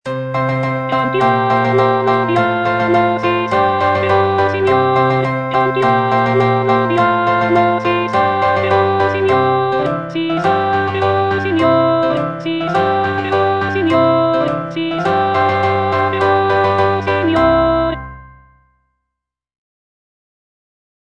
W.A. MOZART - CHOIRS FROM "LE NOZZE DI FIGARO" KV492 Cantiamo, lodiamo, sì saggio signor - Alto (Voice with metronome) Ads stop: